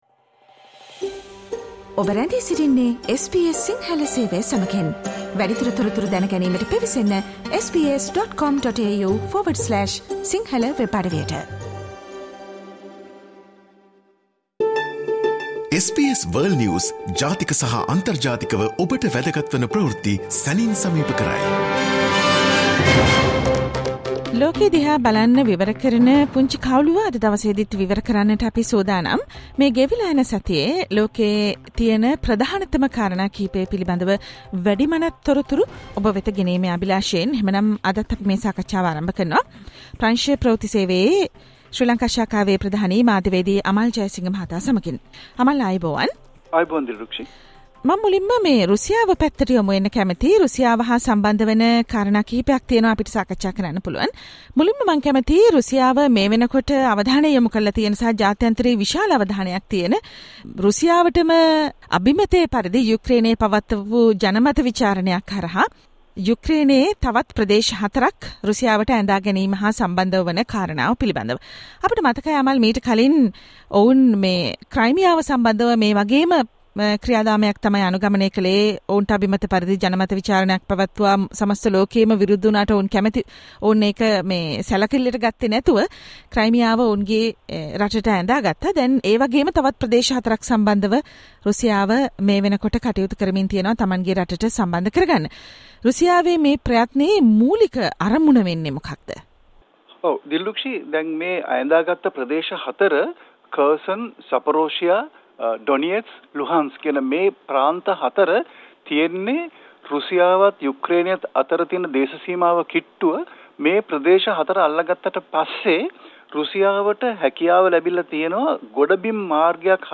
and the world news critic World's prominent news highlights in 13 minutes - listen to the SBS Sinhala Radio weekly world News wrap every Friday Share